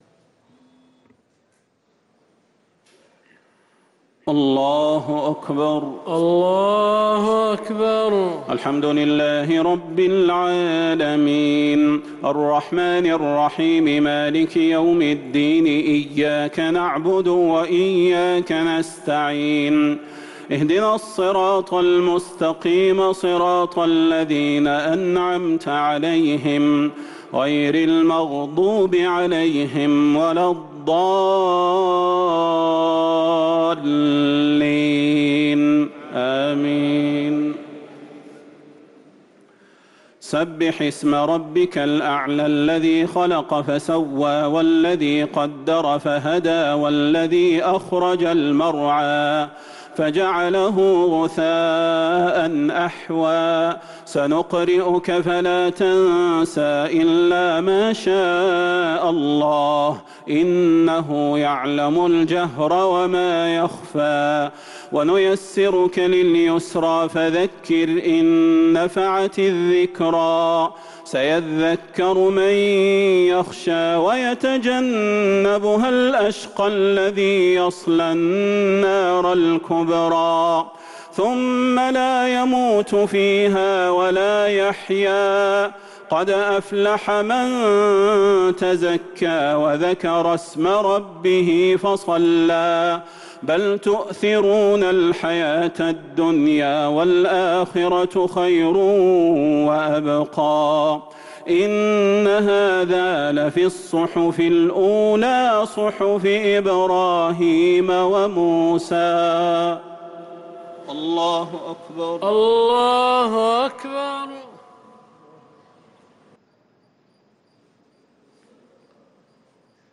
الشفع و الوتر ليلة 25 رمضان 1444هـ | Witr 25 st night Ramadan 1444H > تراويح الحرم النبوي عام 1444 🕌 > التراويح - تلاوات الحرمين